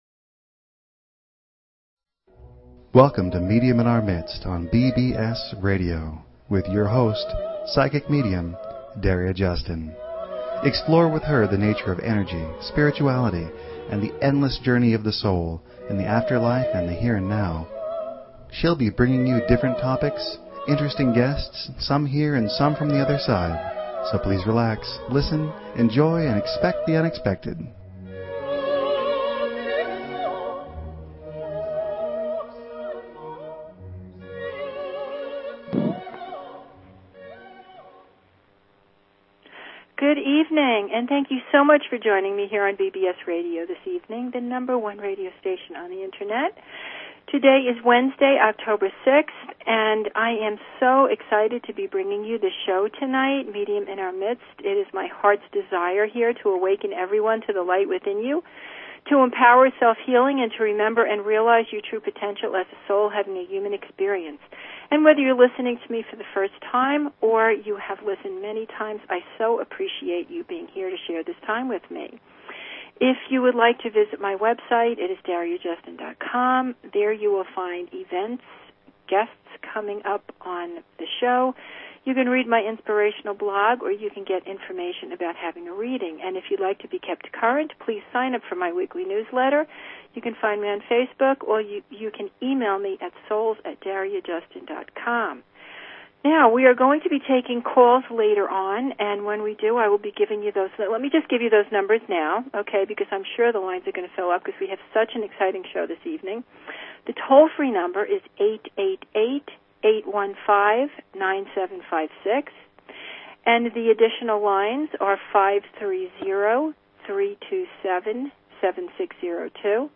Talk Show Episode, Audio Podcast, Medium_in_our_Midst and Courtesy of BBS Radio on , show guests , about , categorized as